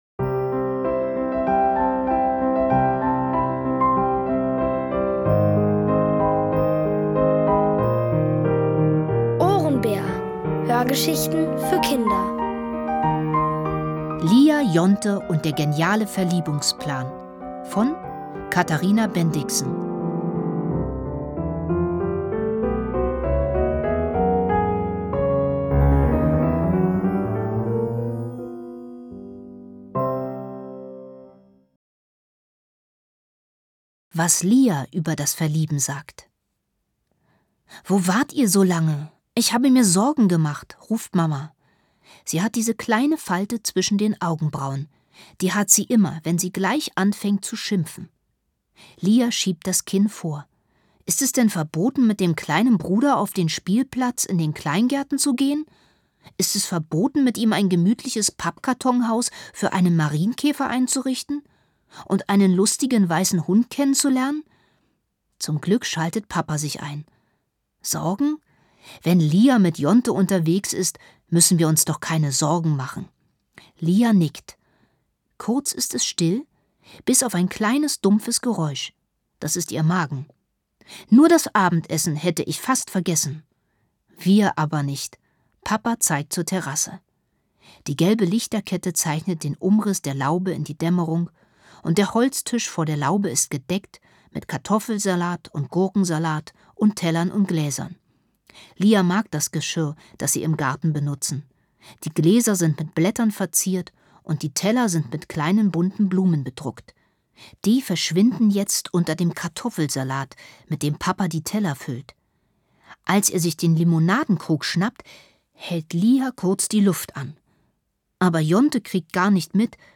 Lia, Jonte und der geniale Verliebungsplan | Die komplette Hörgeschichte!